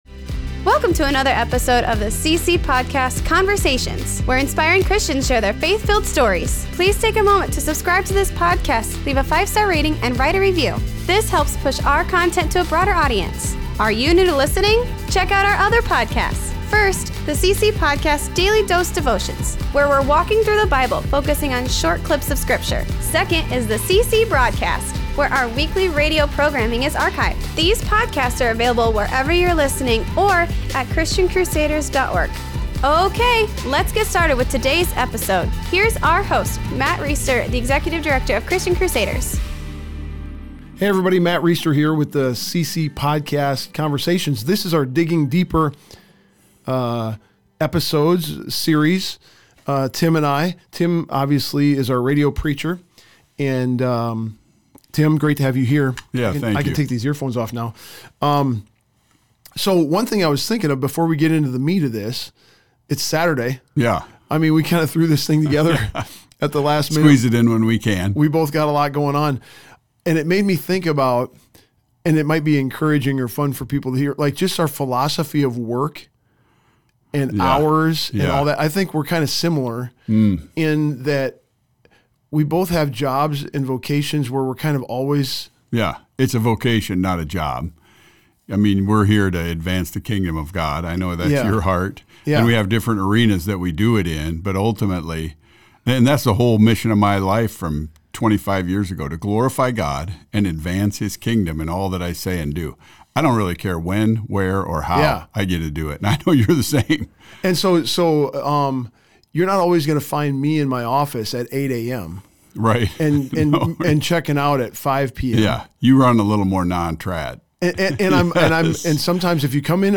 Here are links to the two CC Broadcasts (audio) featuring the two parts of the message referenced in this conversation: